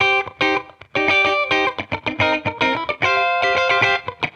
Index of /musicradar/sampled-funk-soul-samples/110bpm/Guitar
SSF_TeleGuitarProc2_110C.wav